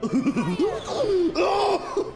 "laugh"